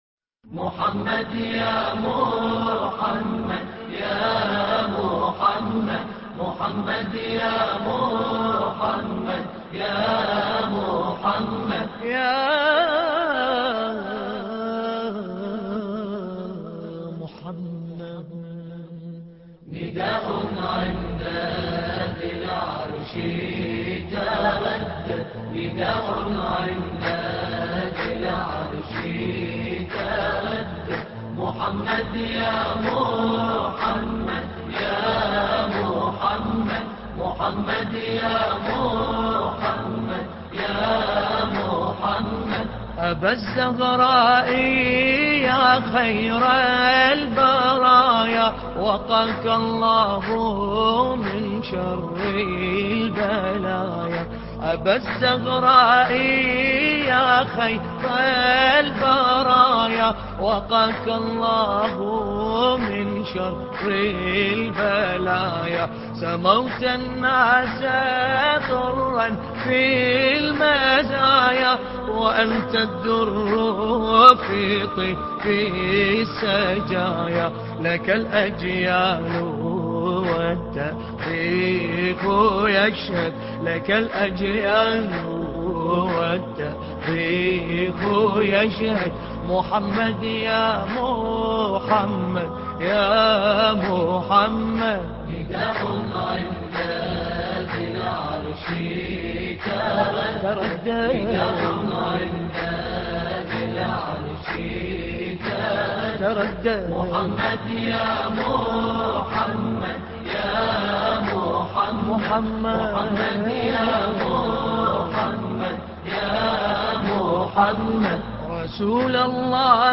نغمة البيات